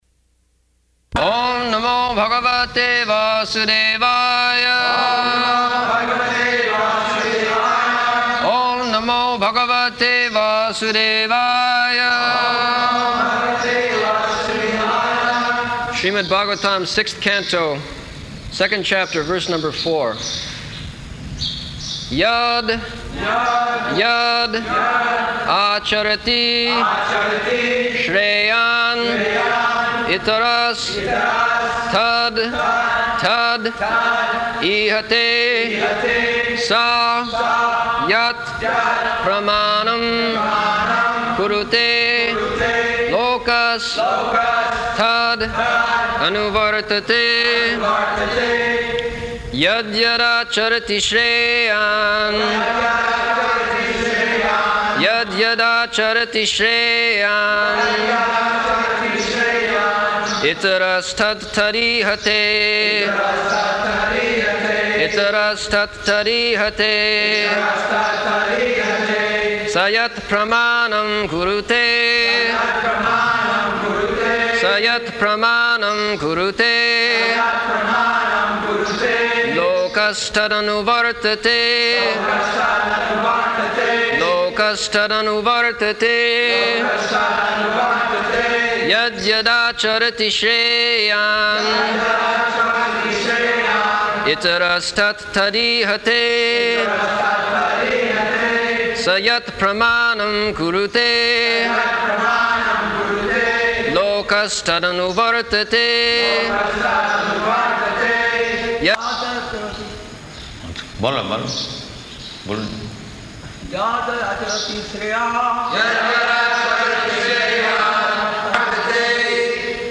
September 8th 1975 Location: Vṛndāvana Audio file
[devotees repeat] Śrīmad-Bhāgavatam, Sixth Canto, Second Chapter, verse number four. [leads chanting of verse, etc.]